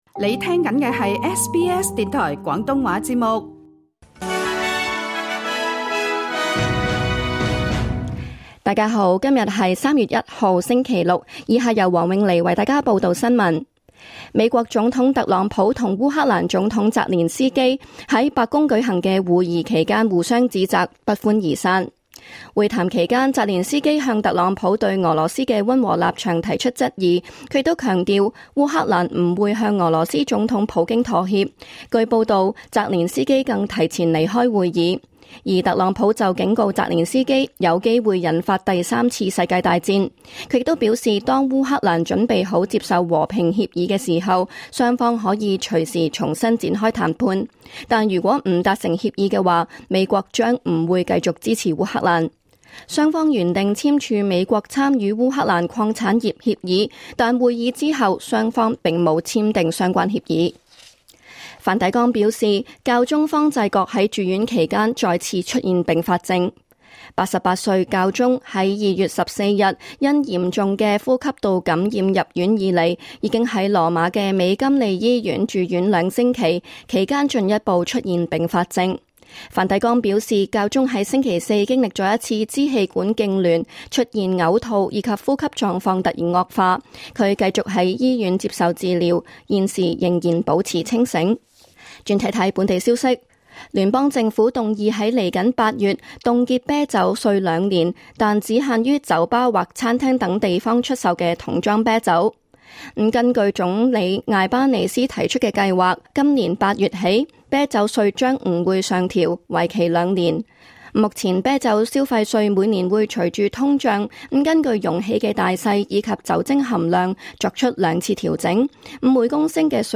2025 年 3月 1日 SBS 廣東話節目詳盡早晨新聞報道。